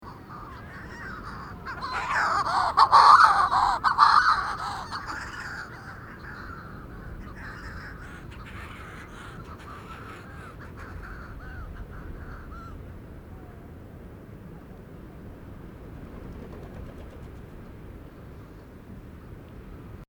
Manx Shearwater Recordings, July 2007, Co. Kerry, Ireland
flight call + wingbeats + burrows squeak